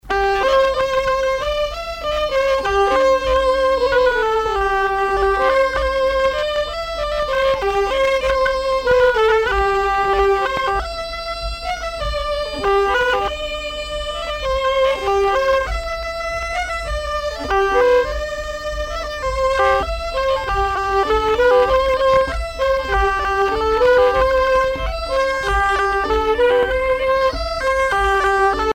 danse : pastillet
Pièce musicale éditée